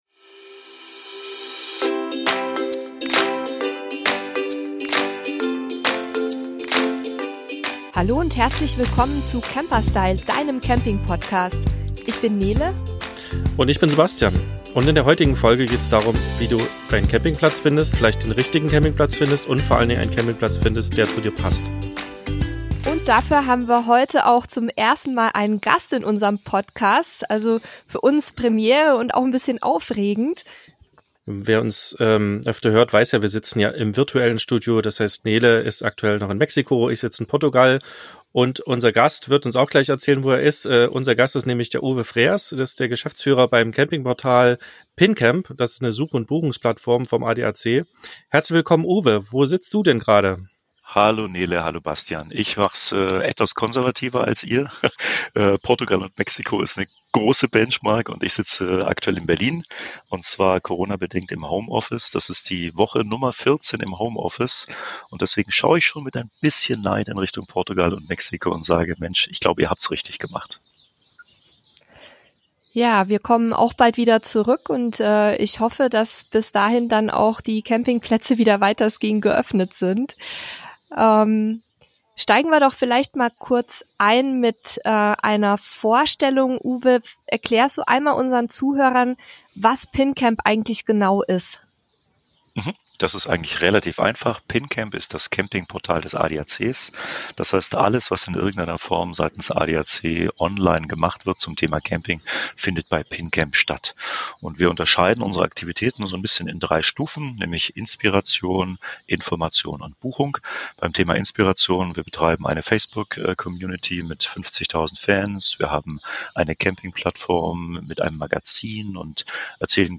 – Der passende Campingplatz für Wohnwagen und Wohnmobil – Dies ist die erste Episode wo wir einen Gast im Podcast begrüßen dürfen.